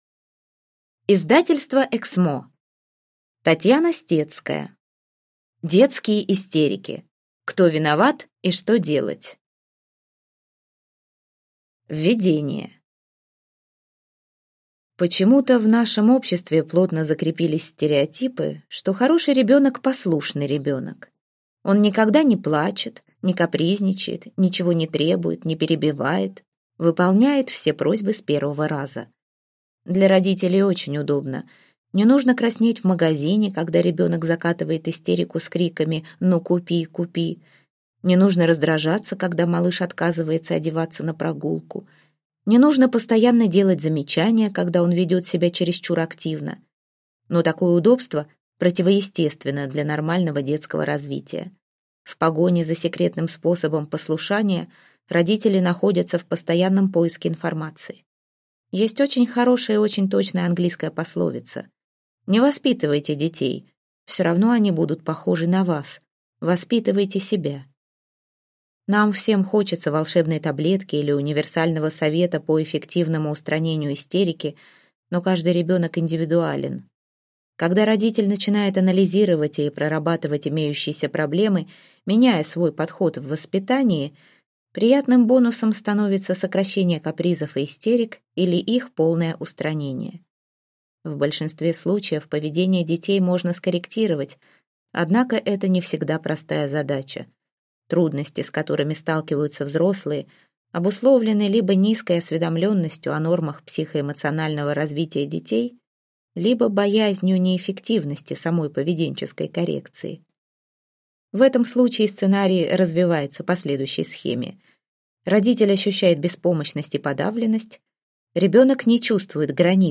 Аудиокнига Детские истерики: кто виноват и что делать?